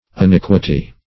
Search Result for " unequity" : The Collaborative International Dictionary of English v.0.48: Unequity \Un*eq"ui*ty\, n. Want of equity or uprightness; injustice; wickedness; iniquity.